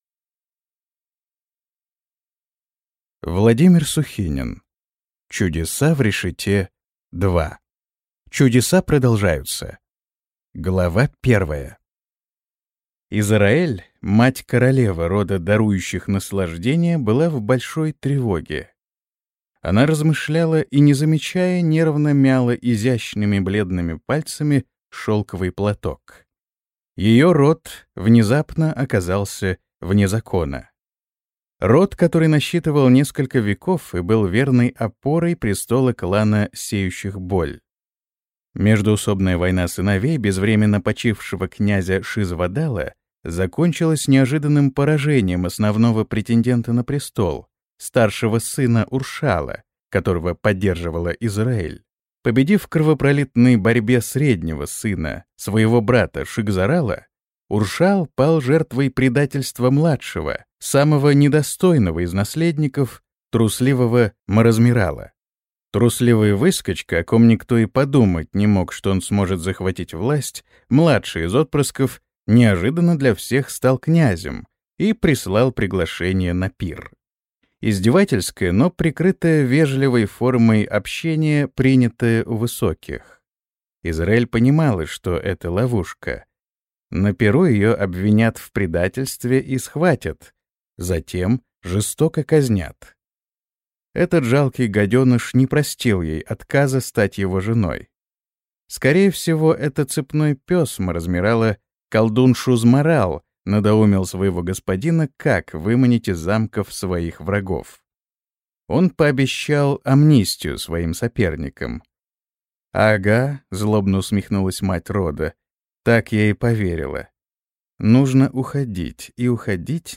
Аудиокнига Чудеса продолжаются | Библиотека аудиокниг